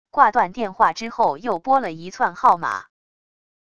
挂断电话之后又拨了一窜号码wav音频